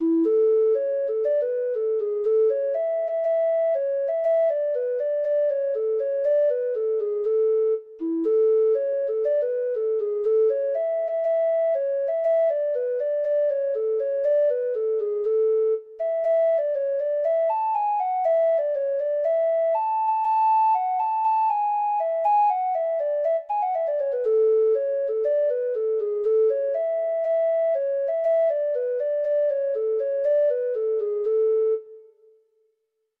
Traditional Trad. The Jolly Plowman (Irish Folk Song) (Ireland) Treble Clef Instrument version
Irish